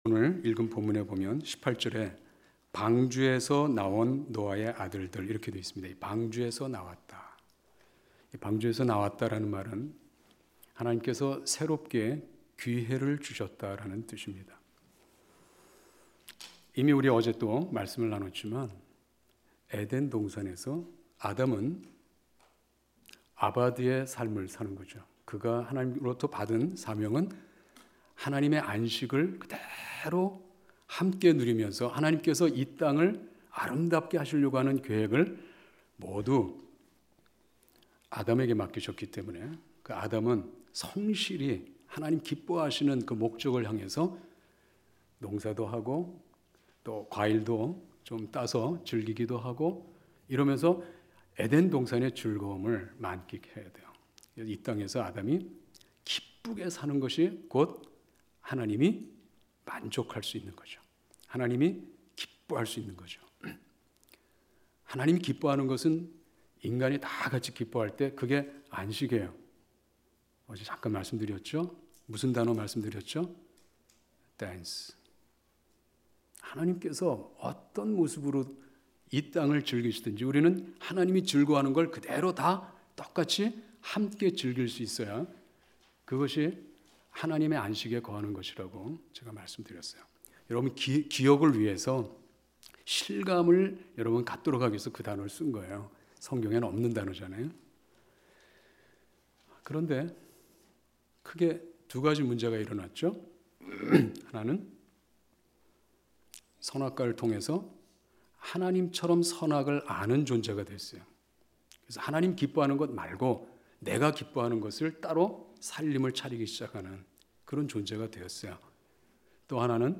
2013년 가을 말씀 사경회